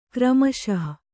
♪ kramaśah